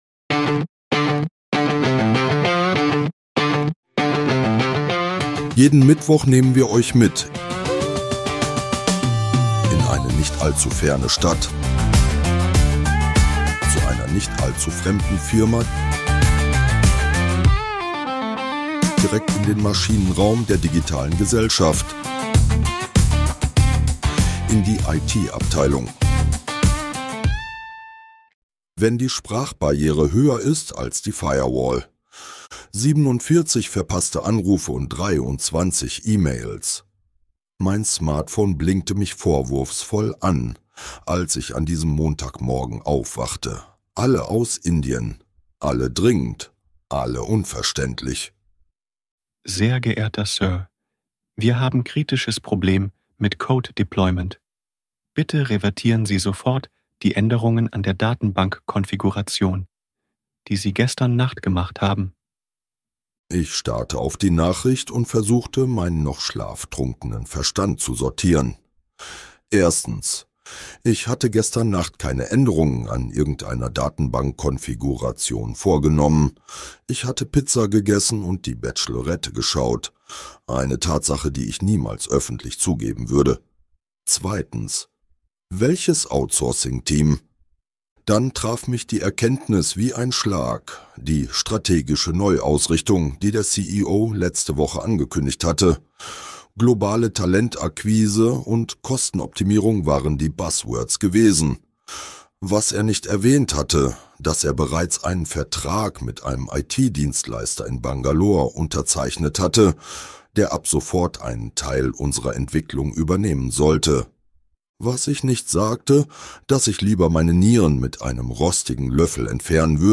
Dieser Podcast ist Comedy.
(AI generiert) Mehr